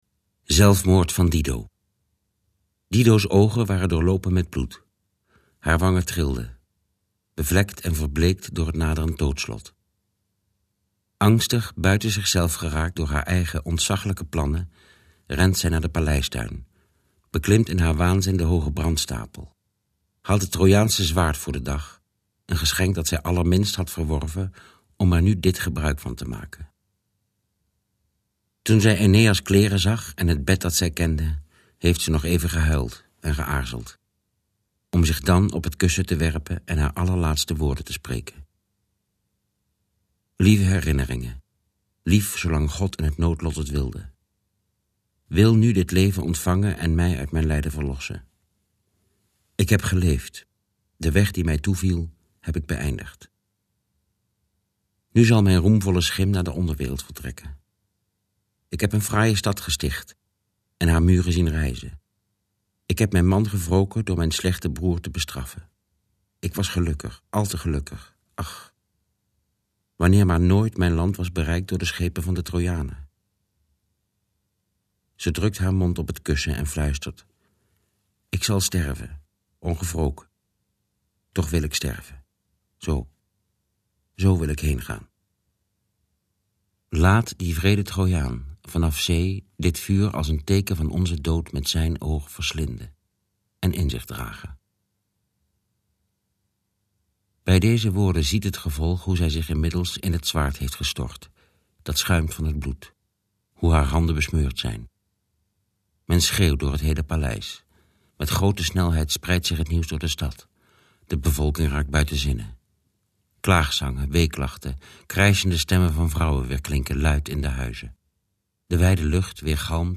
Beluister een fragment uit boek 4 van de Aeneas over de zelfmoord van Dido, voorgedragen door Jeroen Willems.